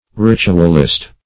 Ritualist \Rit"u*al*ist\, n. [CF. F. ritualiste.]